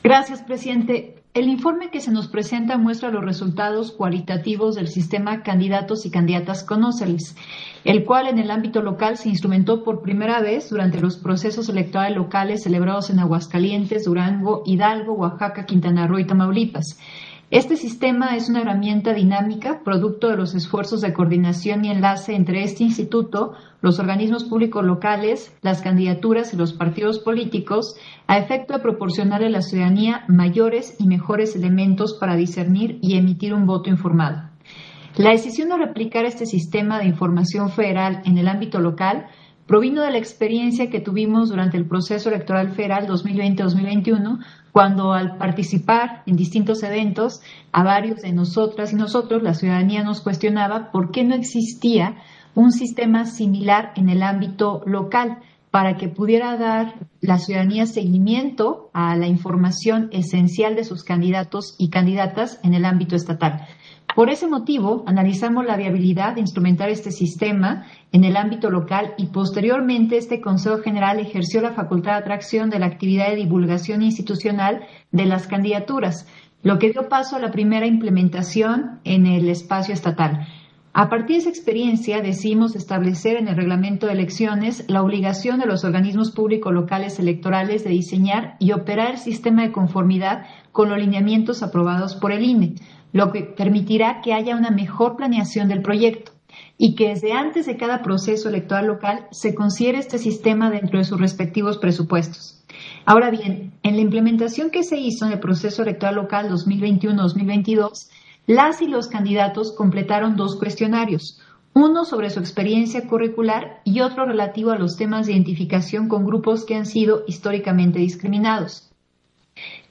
Intervención de Dania Ravel, en Sesión Extraordinaria, relativo al informe de resultados del análisis cualitativo de la información capturada del Sistema Candidatas y Candidatos, conóceles para los procesos electorales 2021-2022